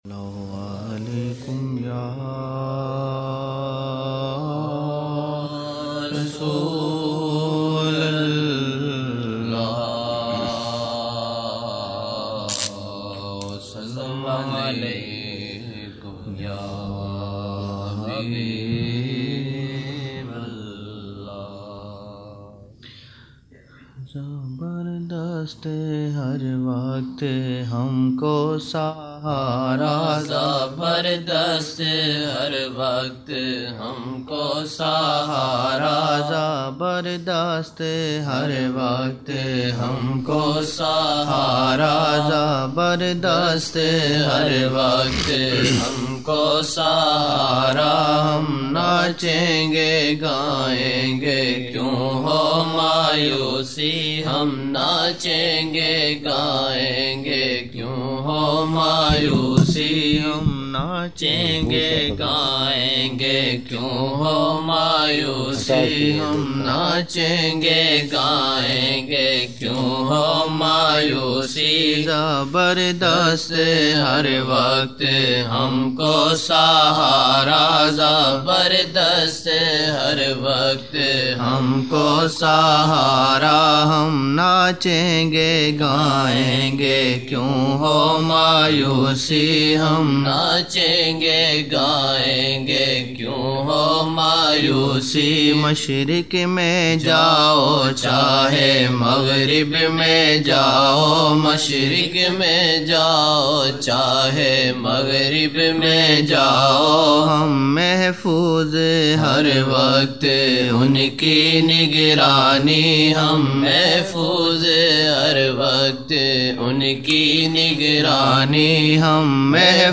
Old Naat Shareef